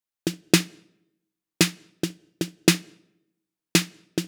06 Snare.wav